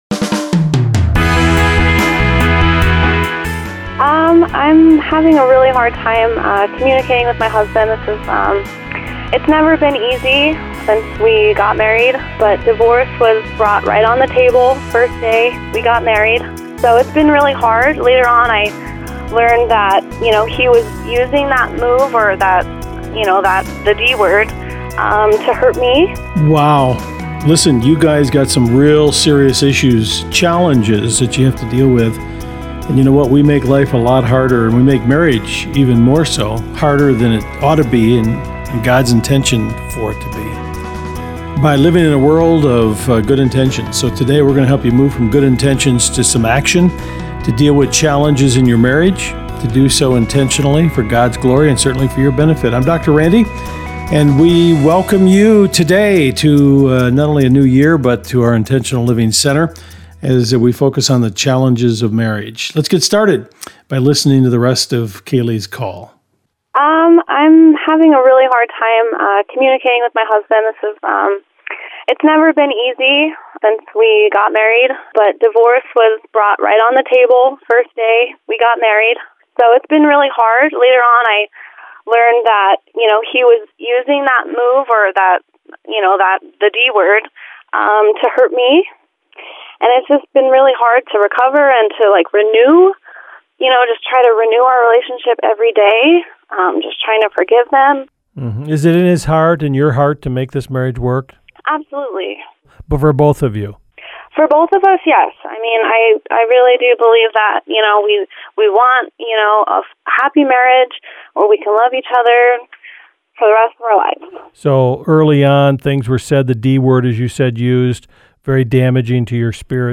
Intentional Living is a nationally-syndicated program on more than 250 radio stations including Family Life Radio.